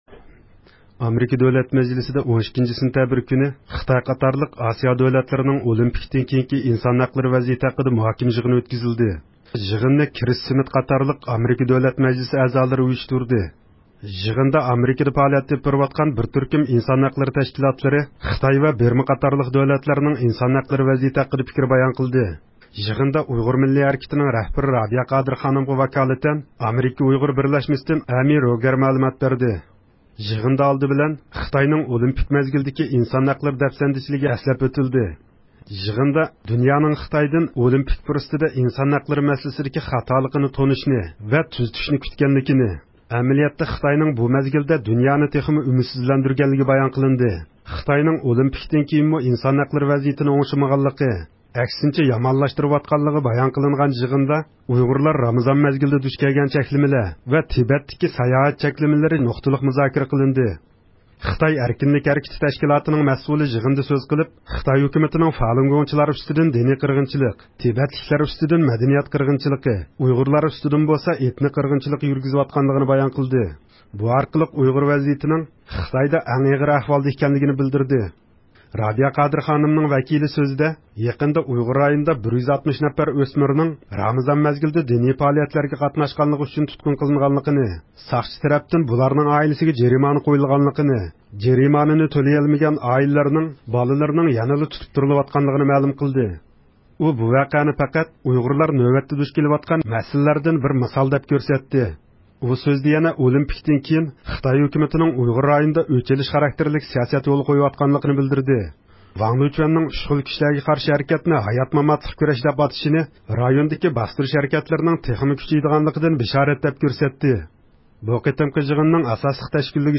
مۇخبىرىمىز